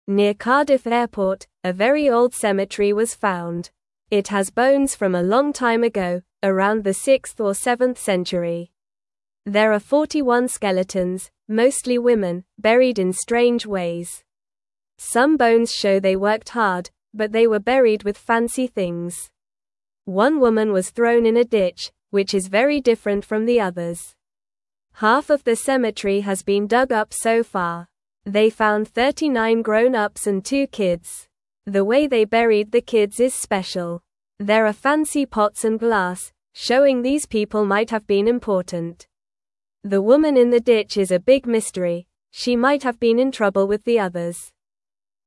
Normal
English-Newsroom-Beginner-NORMAL-Reading-Old-Cemetery-Found-Near-Cardiff-Airport.mp3